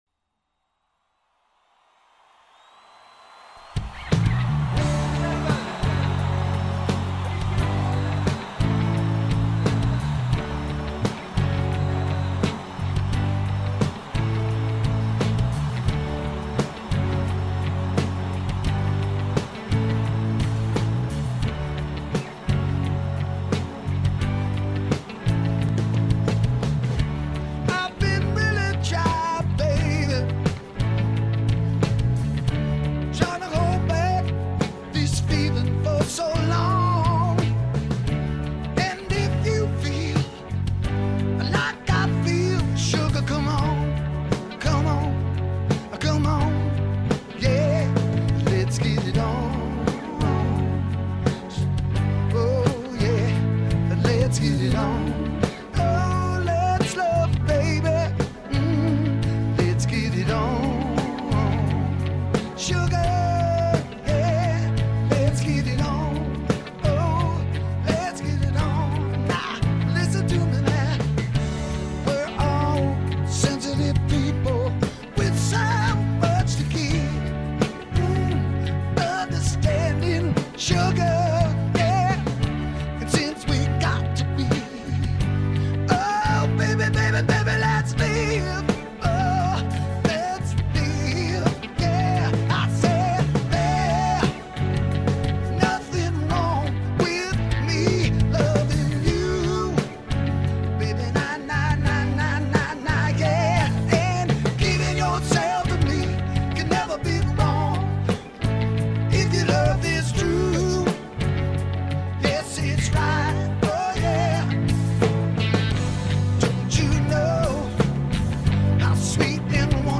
Live Studio Mix